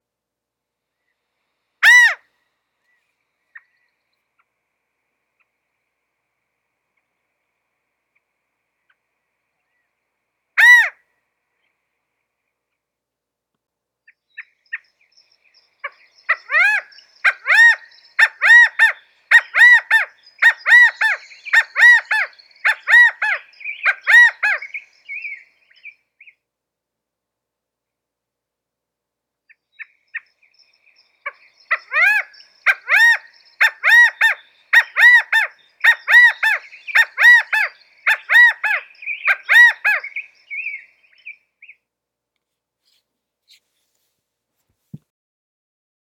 california_quail_calls.m4a